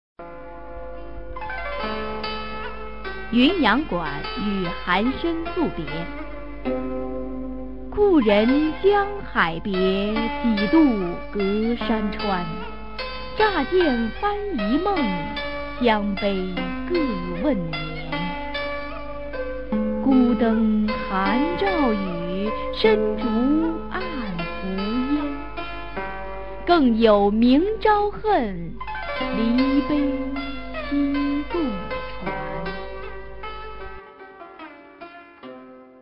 [隋唐诗词诵读]司空曙-云阳馆与韩绅宿别a 配乐诗朗诵